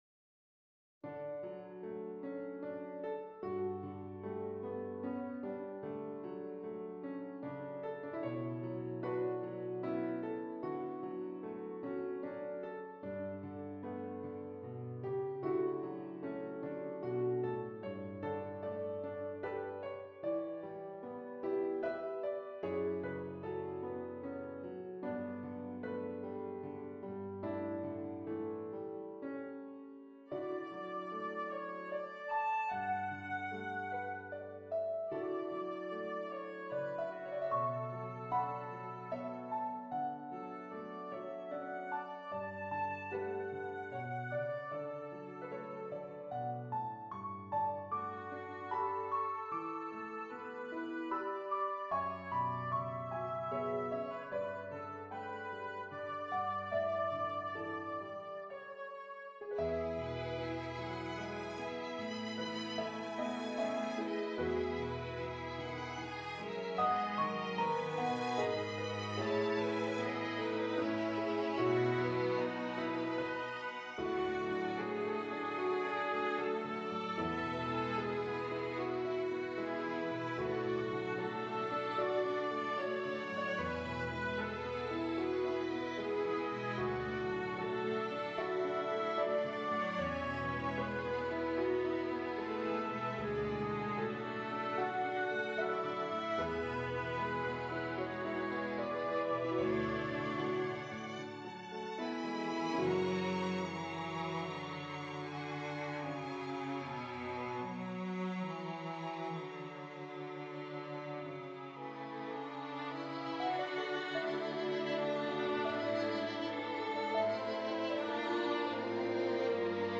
pieza para sexteto de cámara
Esta obra romántica